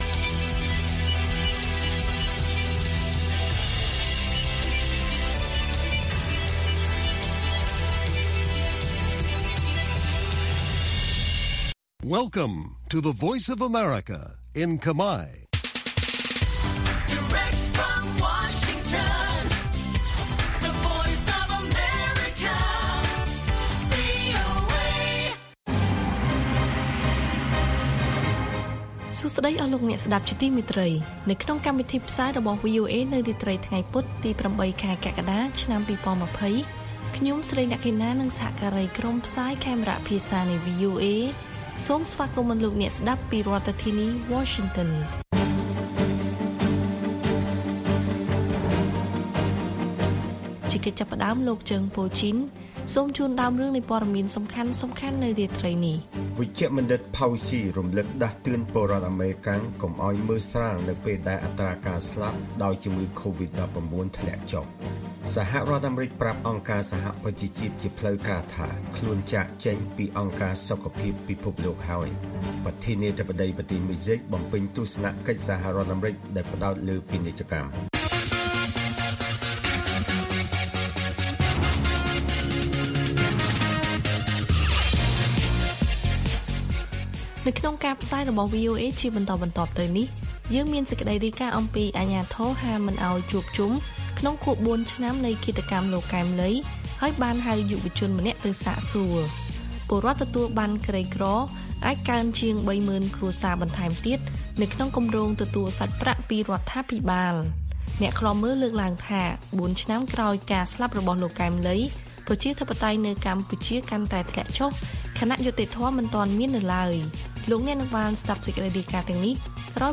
ព័ត៌មានពេលរាត្រី